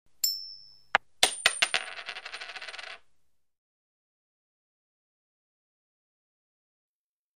Coin Flip | Sneak On The Lot
Coin Flipped And Let Fall To Floor